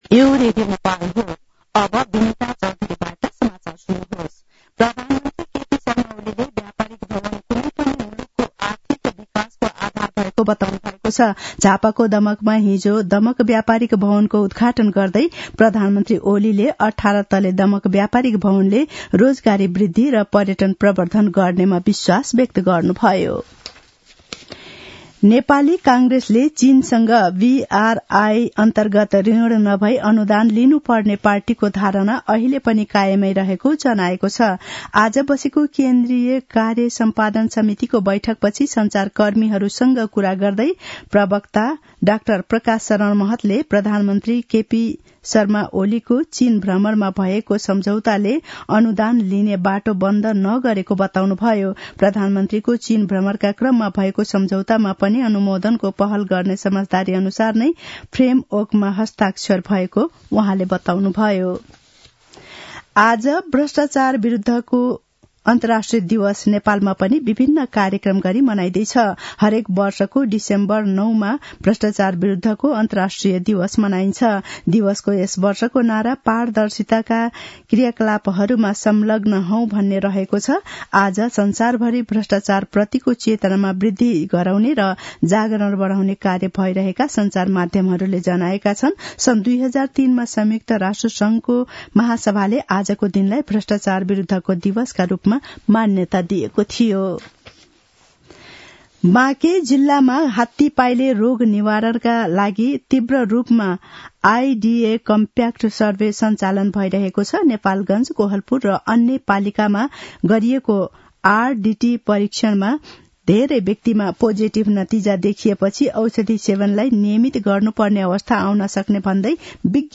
मध्यान्ह १२ बजेको नेपाली समाचार : २५ मंसिर , २०८१
12-am-nepali-news-1-6.mp3